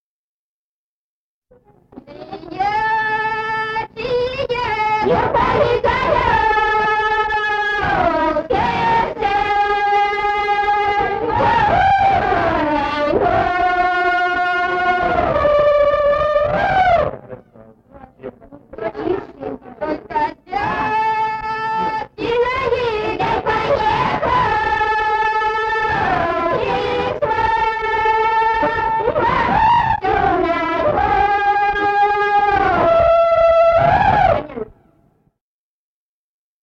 Народные песни Стародубского района «Чие, чие во поле коляски», жнивная.
1954 г., с. Курковичи.